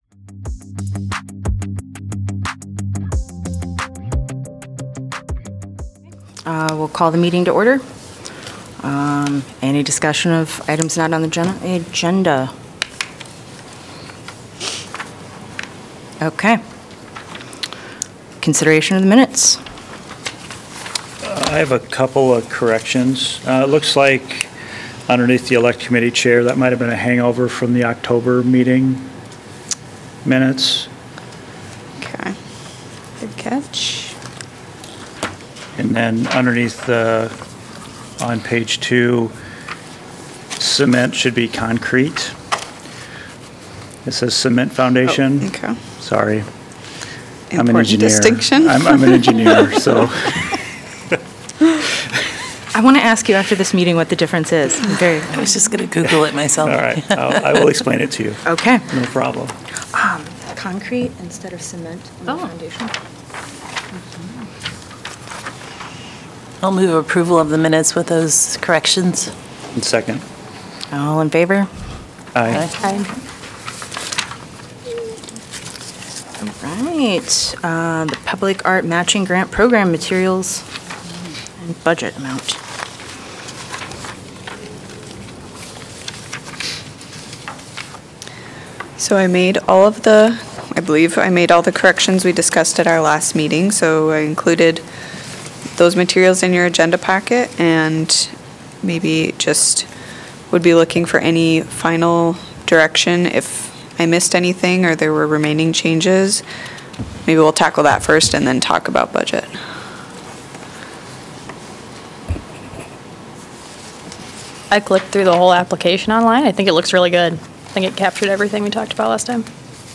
The regular monthly meeting of the Public Art Advisory Committee.